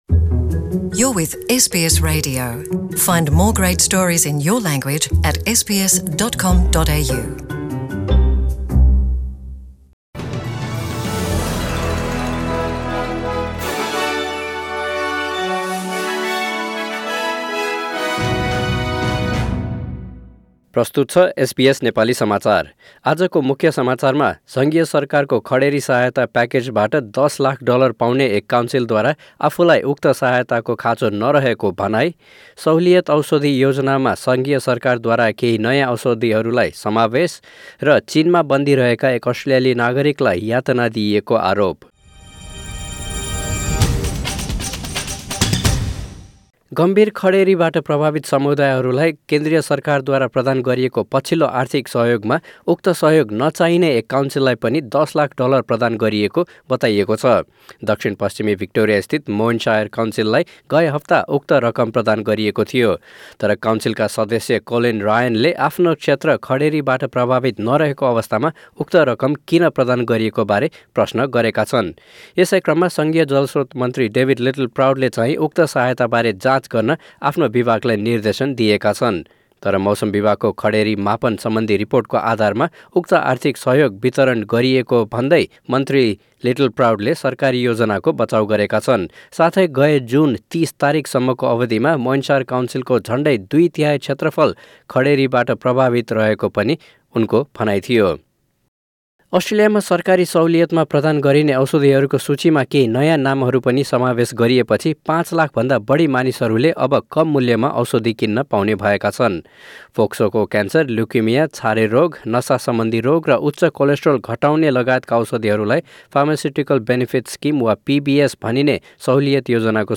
Listen to the latest news headlines in Australia from SBS Nepali radio.